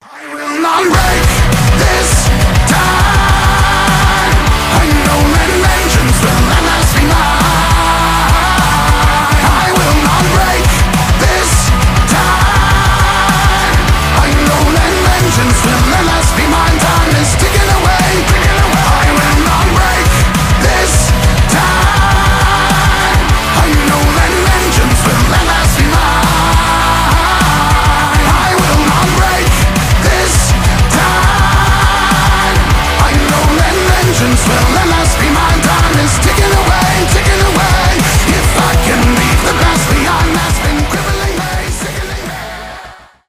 брутальные , альтернатива metal
heavy metal , громкие